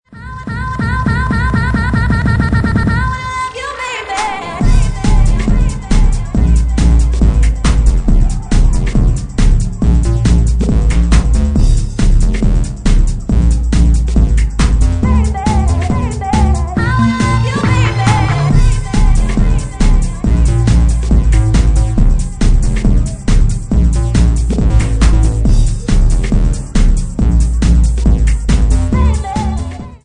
137 bpm